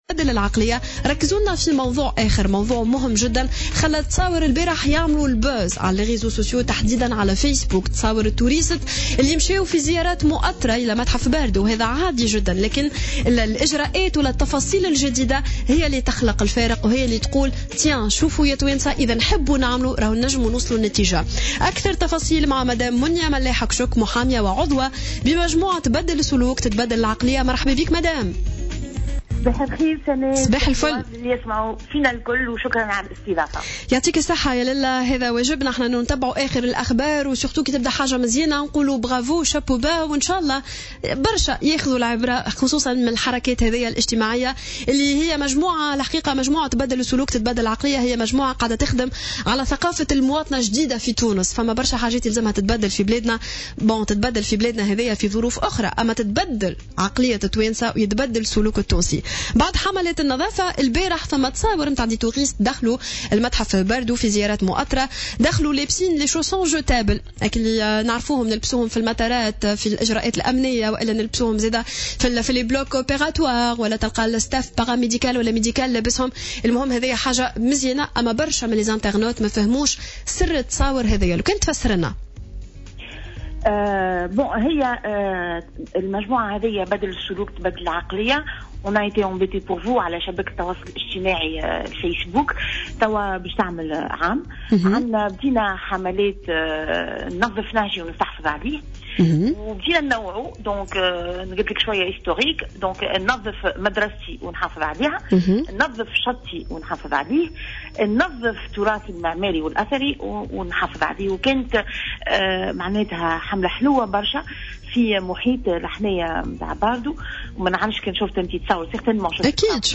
تصريح للجوهرة أف أم في برنامج صباح الورد